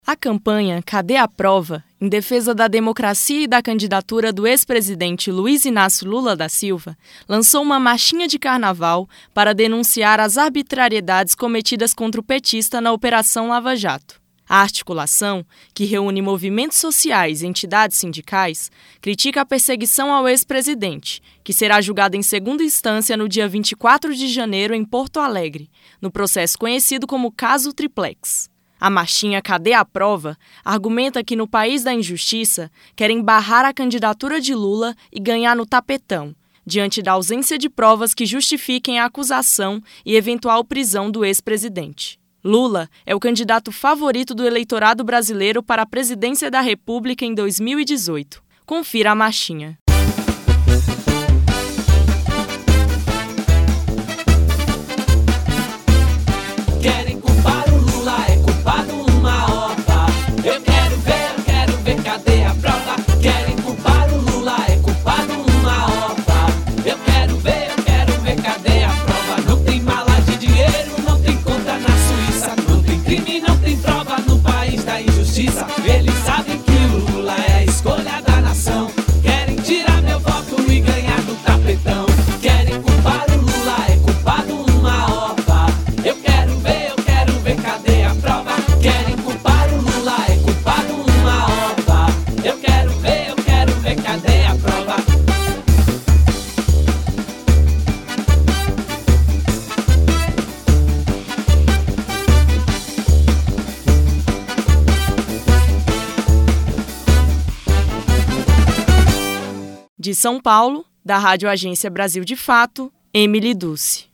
"Cadê a Prova?" Marchinha de carnaval denuncia perseguição a Lula; ouça: